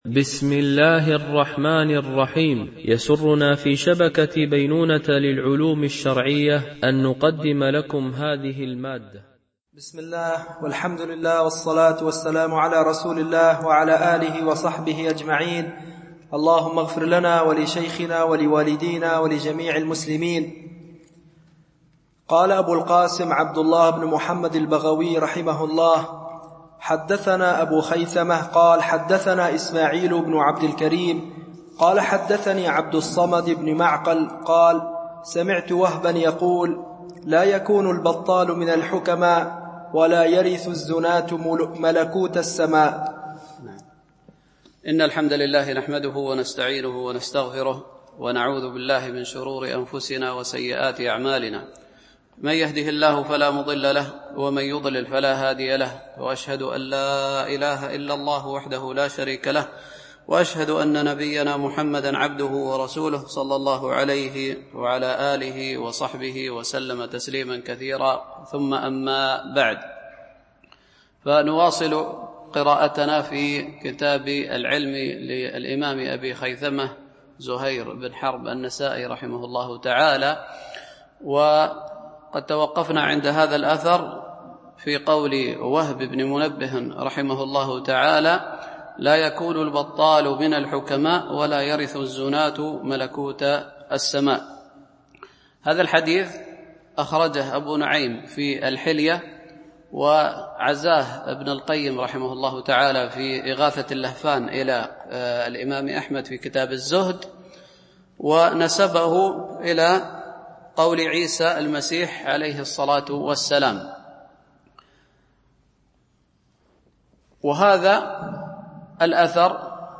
شرح كتاب العلم لأبي خيثمة ـ الدرس 38 (الأثر 127-134)